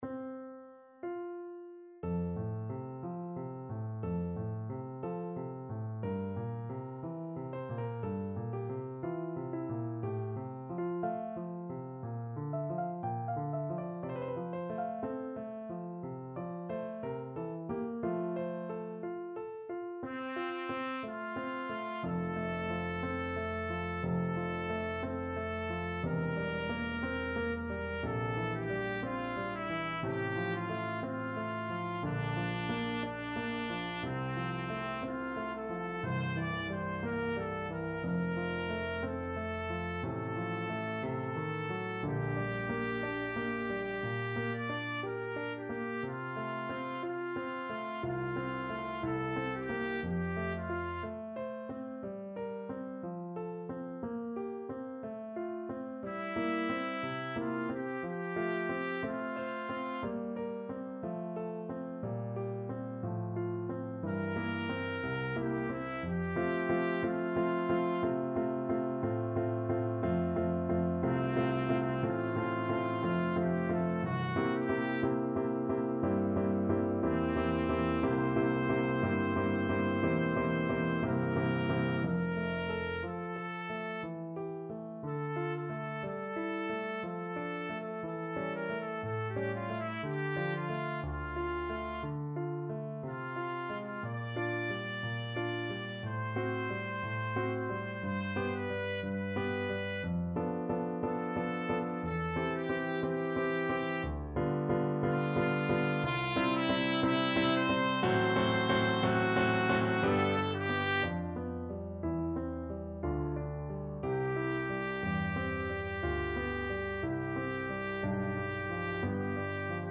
Free Sheet music for Trumpet
Trumpet
F major (Sounding Pitch) G major (Trumpet in Bb) (View more F major Music for Trumpet )
4/4 (View more 4/4 Music)
~ = 60 Larghetto
Classical (View more Classical Trumpet Music)